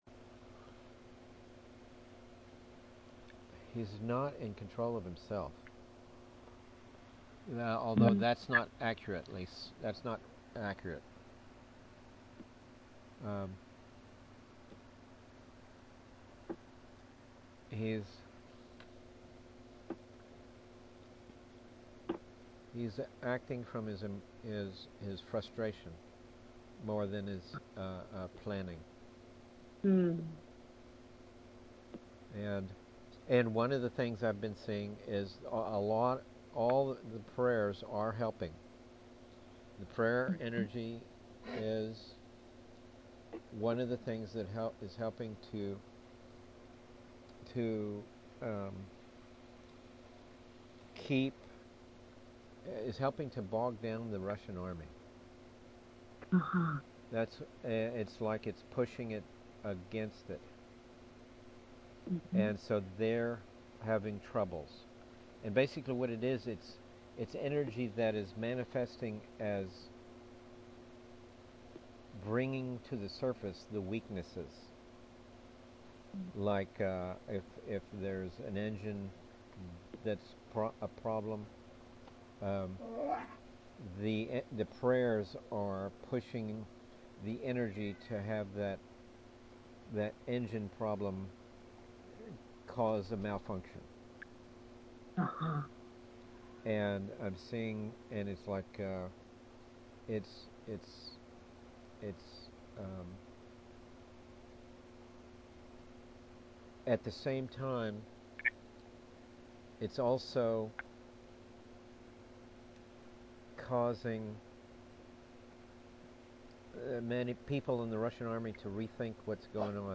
The volume of my voice is low, so you will have to turn up the volume as high as you can to get my voice clearly.
This volume problems is the problem with my own equipment sometimes.
The recording begins after the conversation has started – about 5-10 minutes from the beginning.